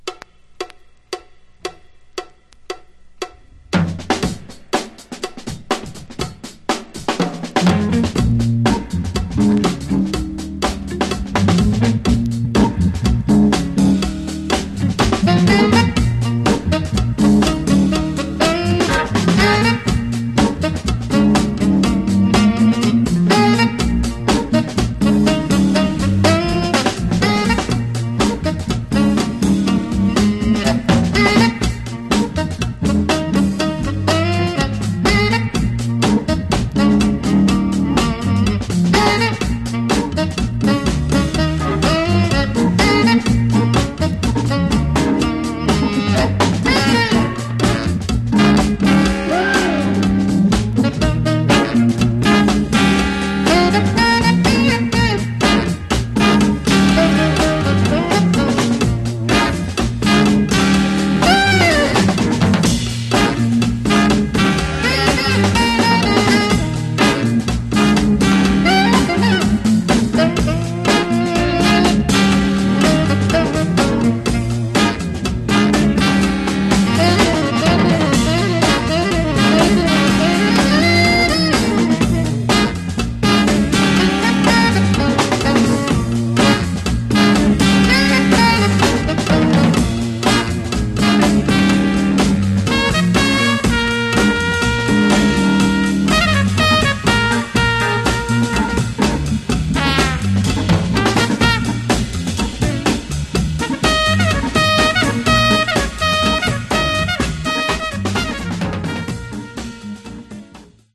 Genre: RnB Instrumentals
Terrific RnB stomper.